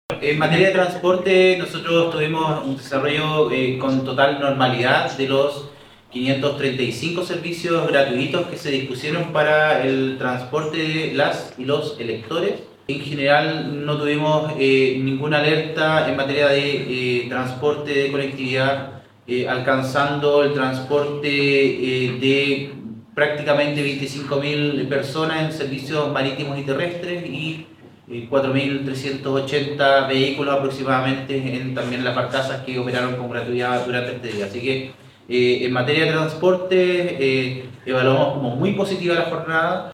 El seremi de Transportes Pablo Joost señaló que los medios terrestres y marítimos tuvieron un desempeño dentro de lo esperado y pudieron trasladar a los electores a sus locales de votación.